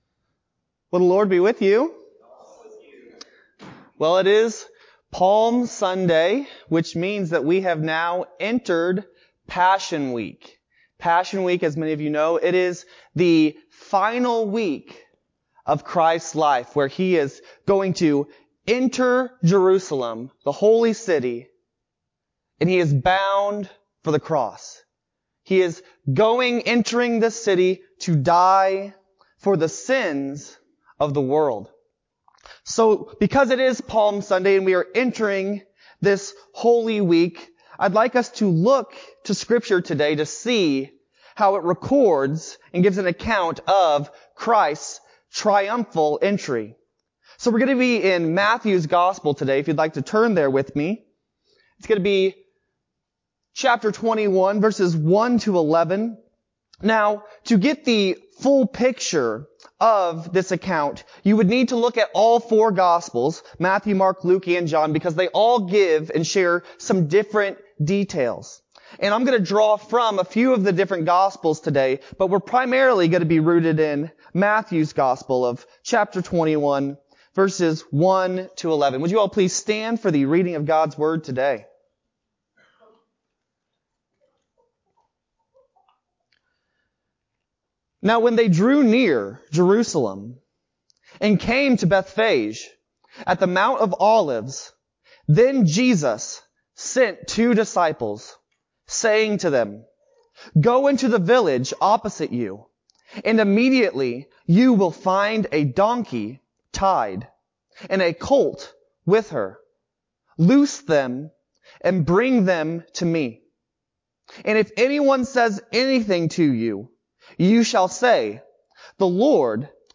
3-28-21-Sermon-CD.mp3